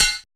CLANG PERC.wav